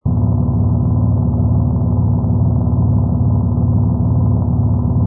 rumble_utility_small.wav